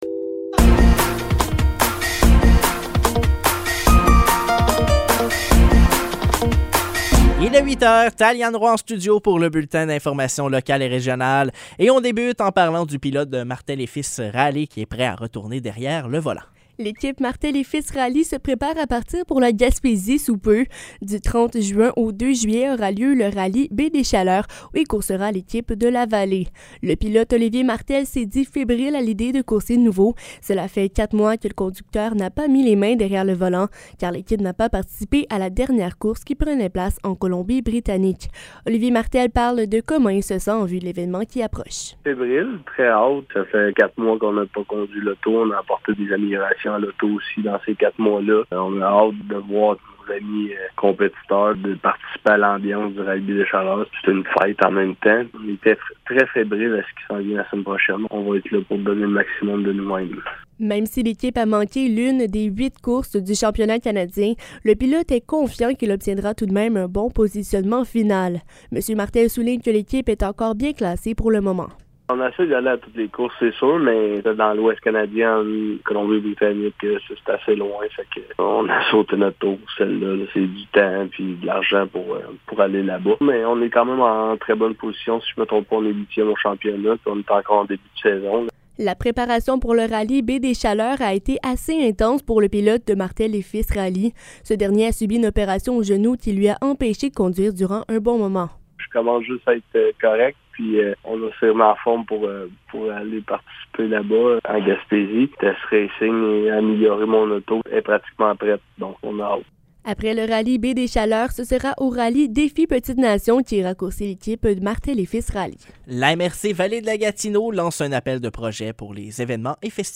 Nouvelles locales - 21 juin 2023 - 8 h